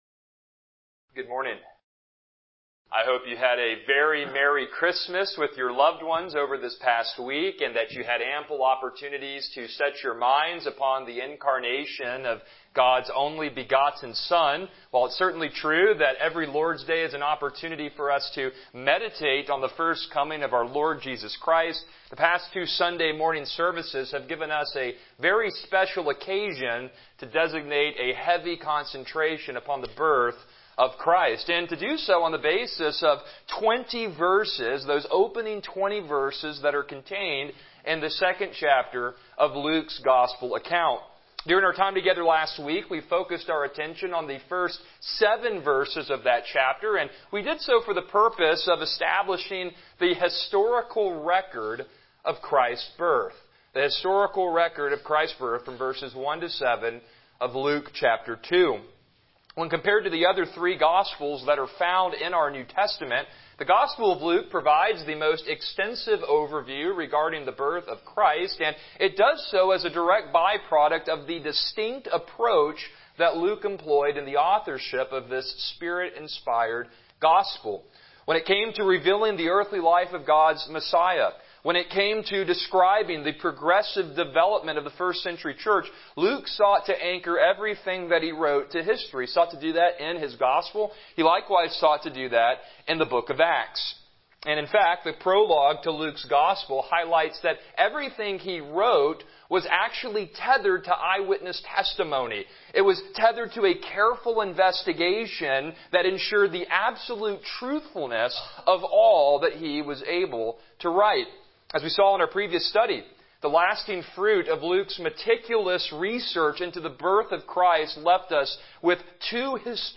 Passage: Luke 2:8-20 Service Type: Morning Worship